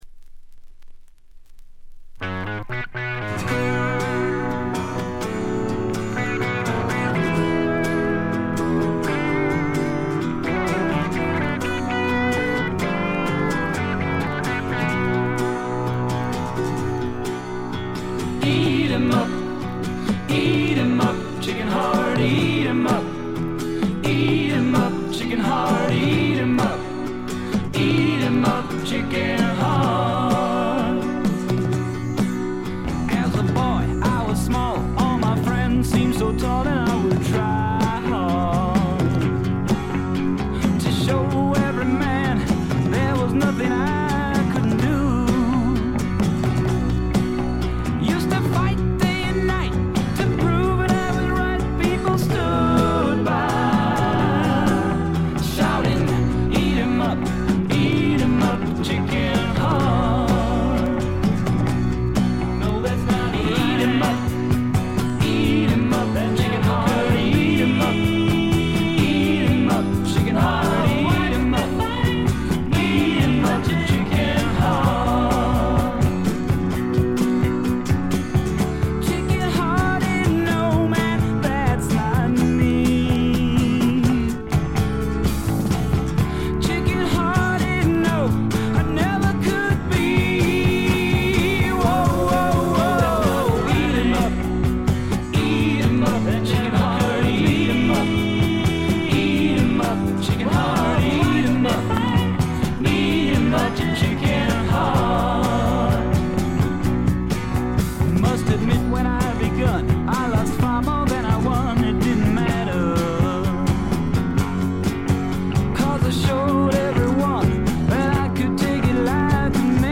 軽いチリプチ程度。
南アフリカのビートルズ風ポップ・ロック・バンド。
試聴曲は現品からの取り込み音源です。
Vocals, Acoustic Guitar
Vocals, Tambourine, Tabla